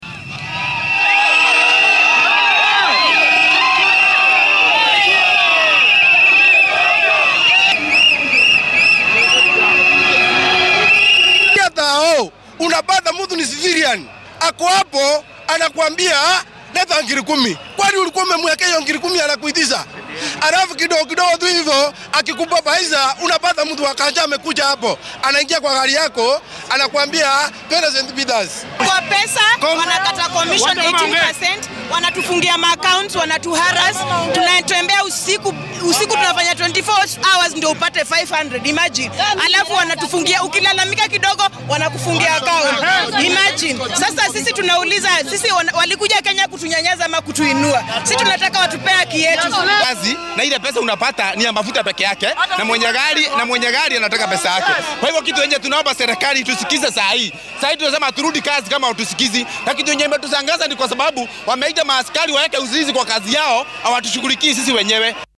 Qaar ka mid ah darawaliinta maanta dibadbaxa dhigay ayaa dareenkooda la wadaagay warbaahinta.